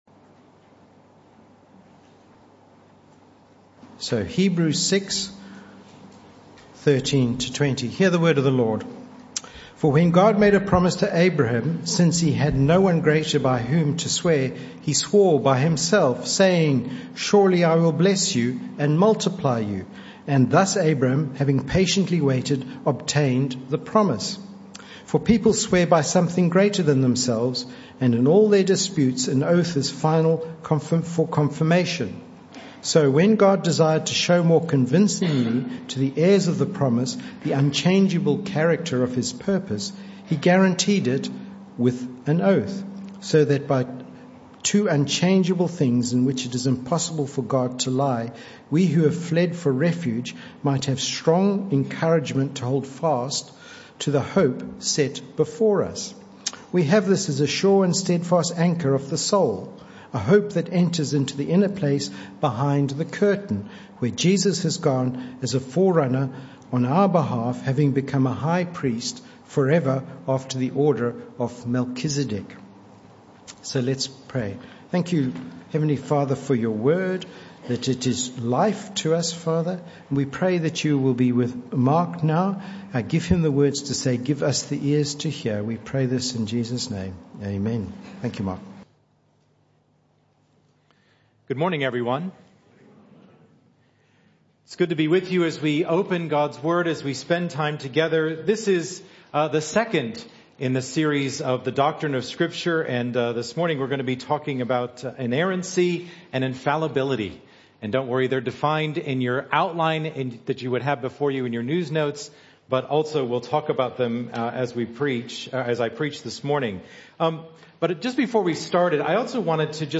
This talk was part of the AM Service series entitled The Doctrine Of Scripture: Our Foundation For Life (Talk 2 of 5).